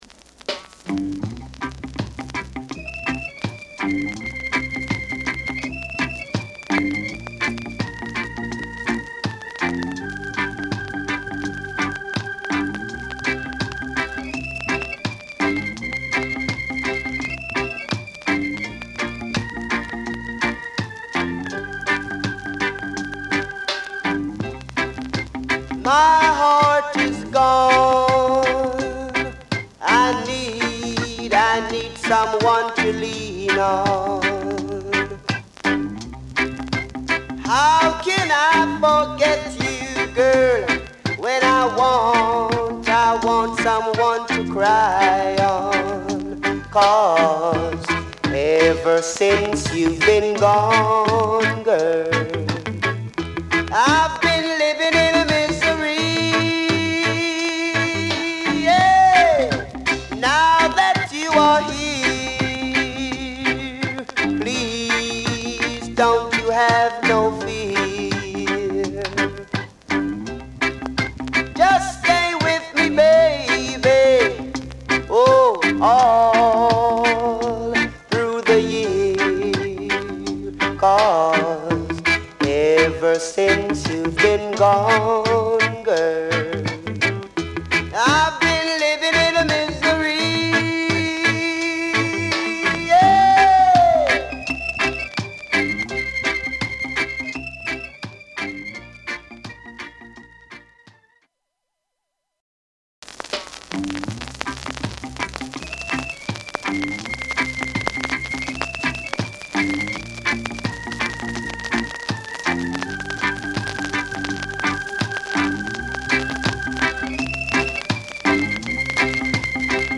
Genre: Rocksteady / Early Reggae